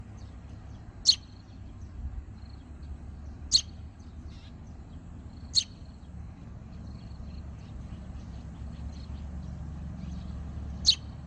树麻雀幼鸟叫声